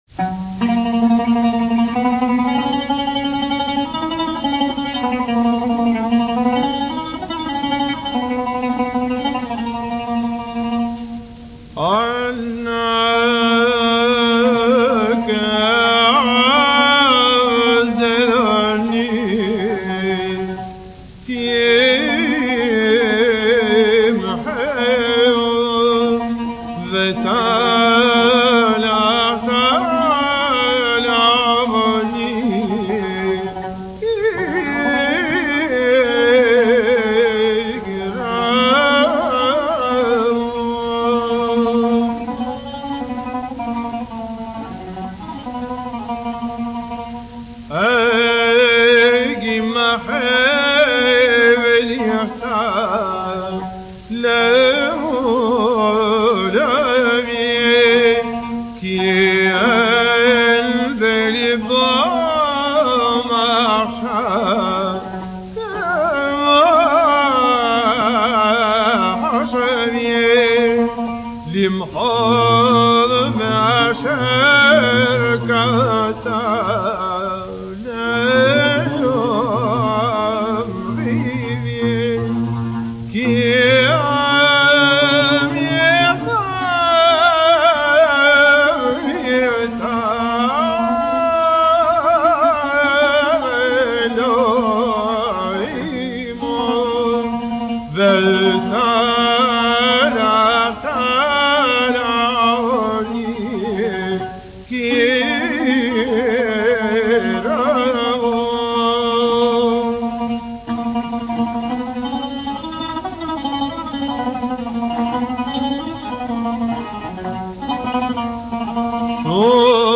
Las selijot en el rito turco